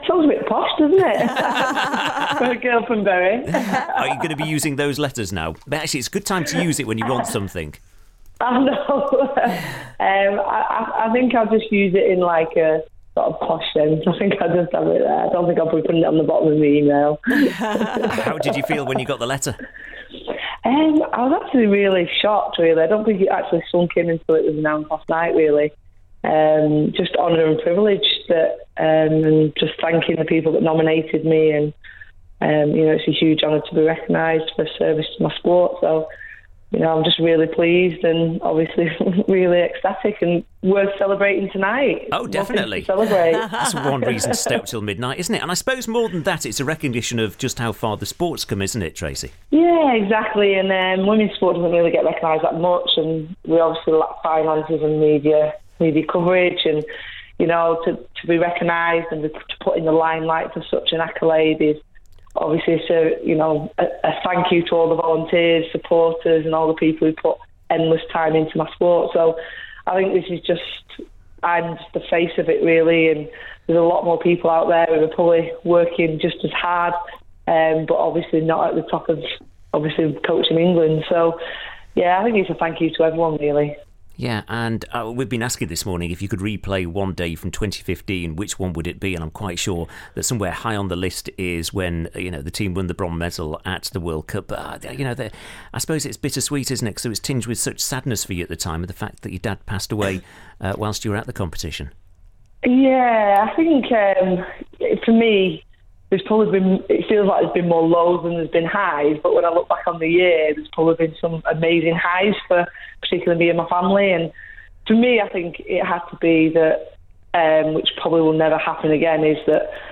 England Netball Head Coach Tracey Neville MBE talks